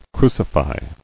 [cru·ci·fy]